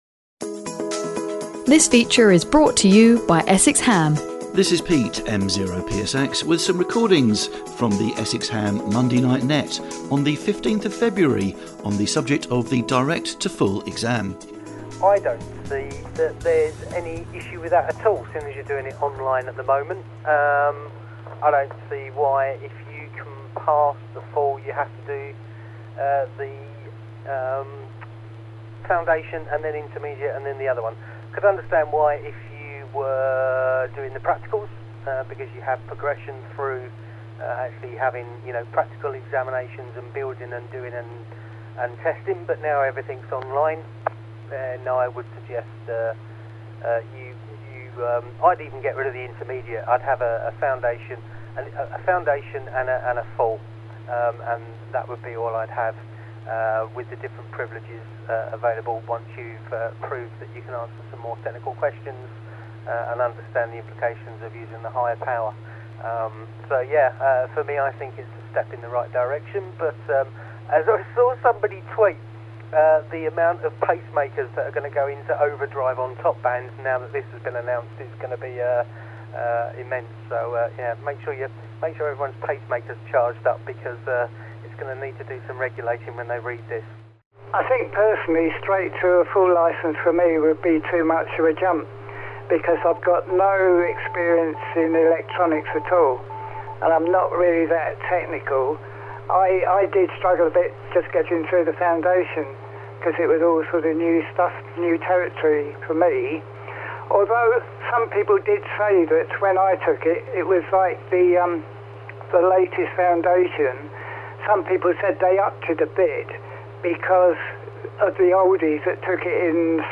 The subject of the proposed new RSGB “Direct to Full” exam was discussed on the Monday Night Net on the 15th of February 2021. Here are the edited highlights of the discussion.